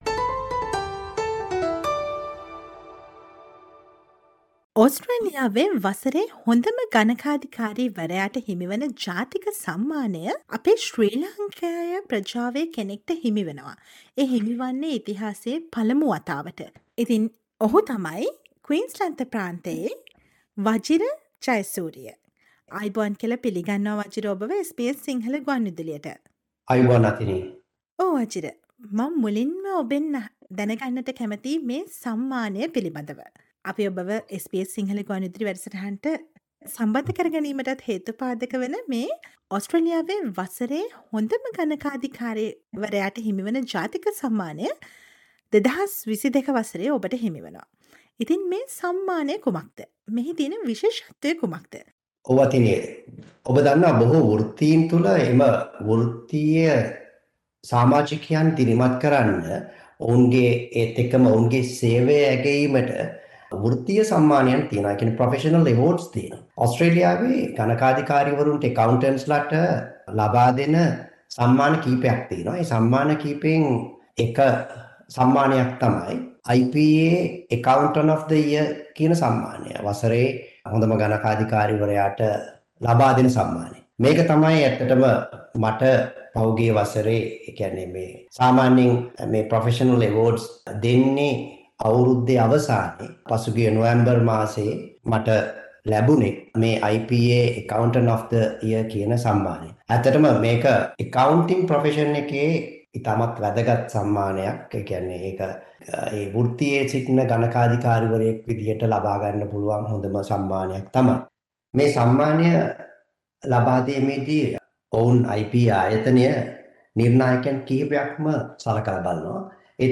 Listen to the SBS Sinhala radio interview